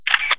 camera.wav